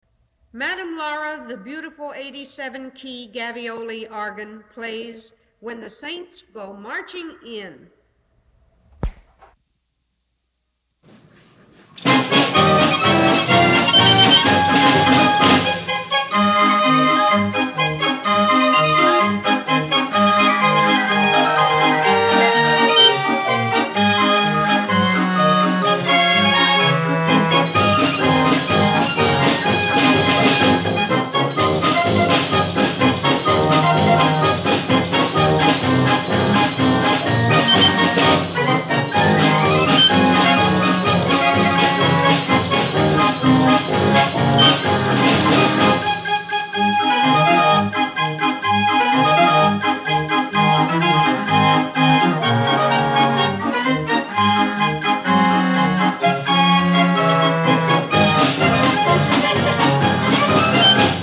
BAND ORGANS/FAIR ORGANS